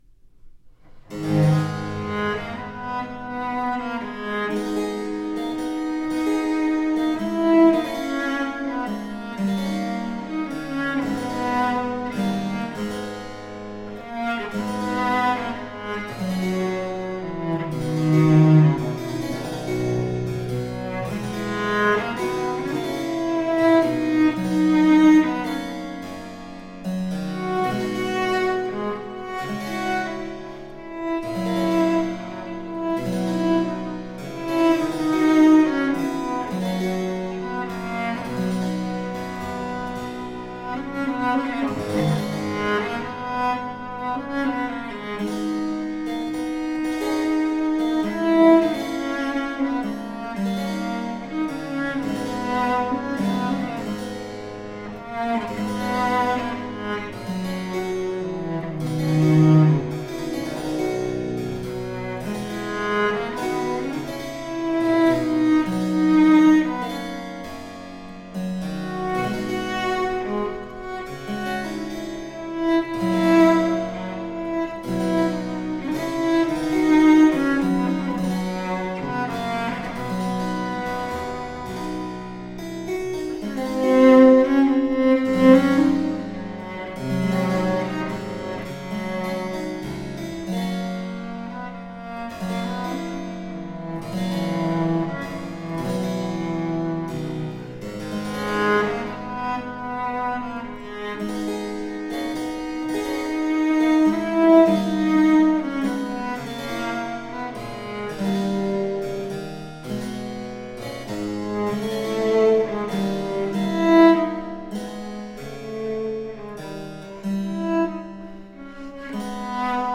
Rare and extraordinary music of the baroque.
Classical, Baroque, Instrumental
Harpsichord